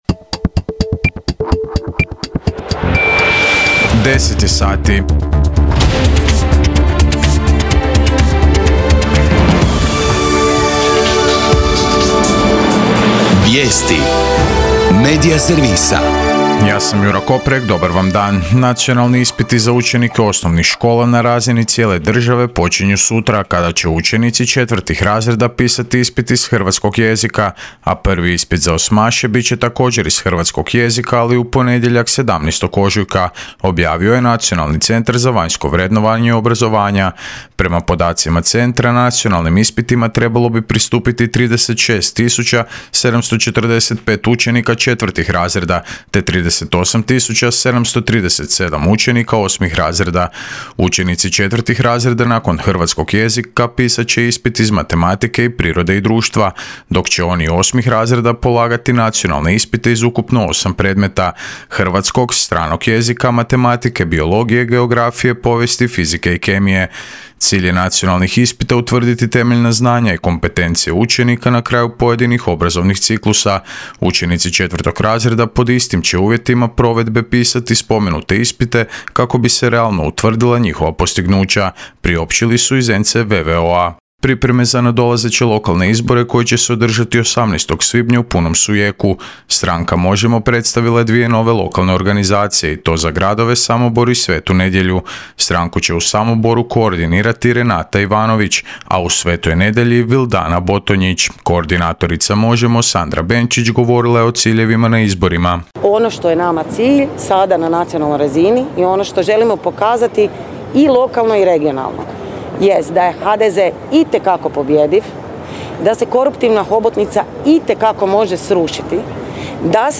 VIJESTI U 10